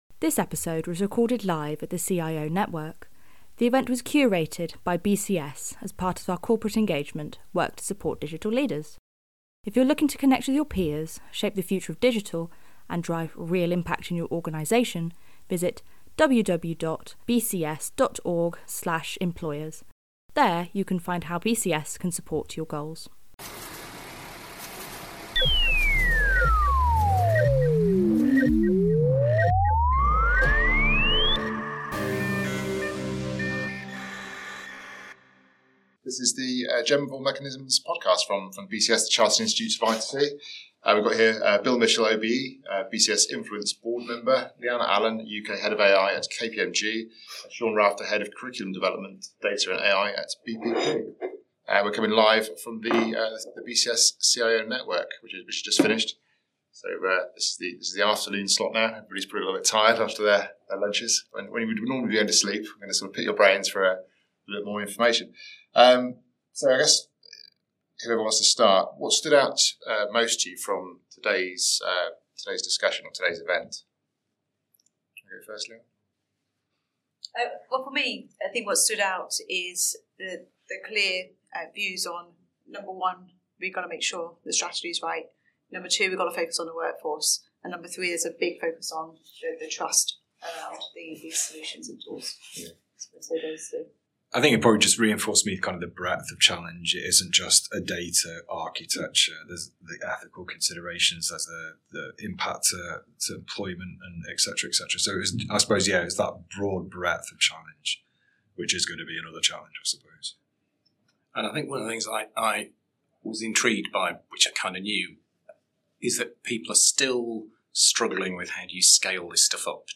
Following the event, key speakers appeared in a special edition of the Gem of All Mechanisms podcast discussing how AI use can be transformed into real organisational impact as well as sharing their thoughts on the day.